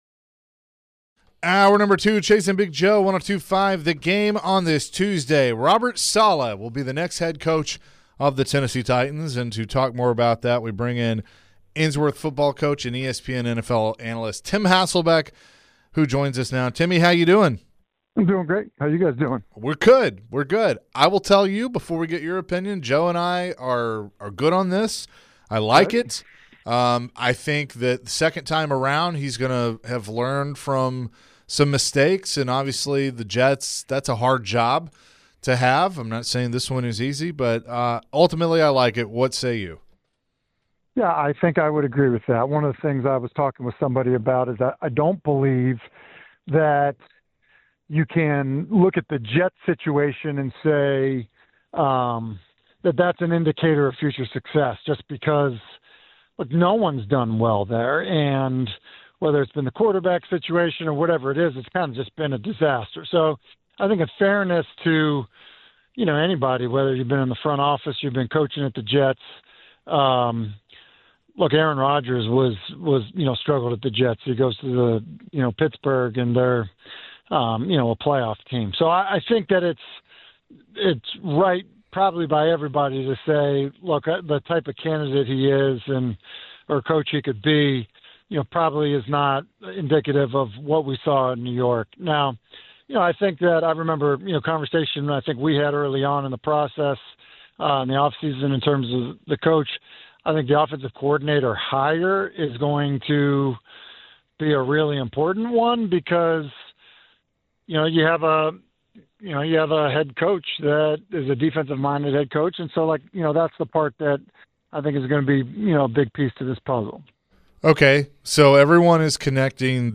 ESPN's NFL Analyst Tim Hasselbeck joined the show nd share dhis thoughts on the Titans' new hire at head coach with Robert Saleh. Tim also shared his thoughts on who the Titans should hire as their offensive coordinator.